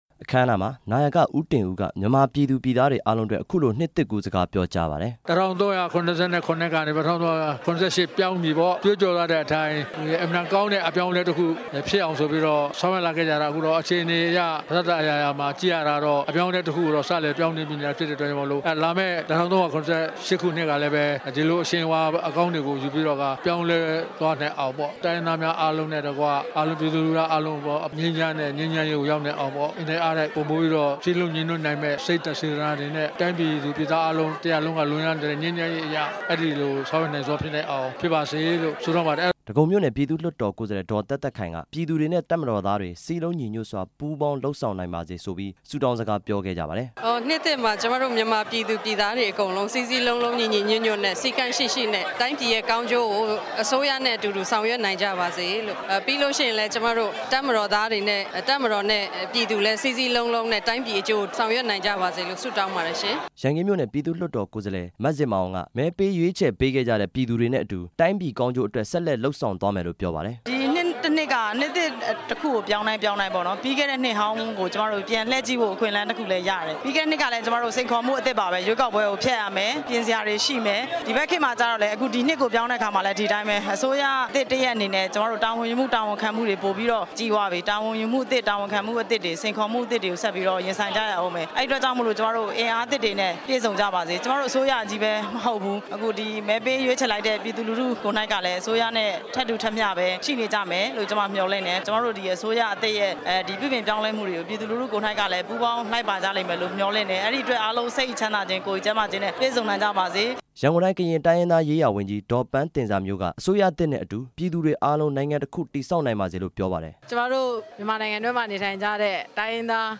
နာယက သူရဦးတင်ဦး၊ ရန်ကုန်တိုင်းဒေသကြီး လွှတ်တော်ဥက္ကဌနဲ့ လွှတ်တော်ကိုယ်စားလှယ် တချို့က နှစ်ကူးနှုတ်ဆက်စကားပြောကြပါတယ်။